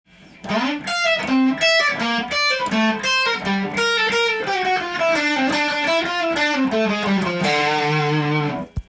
フュージョン系のギターソロになると
細かい音符が多くなる印象です。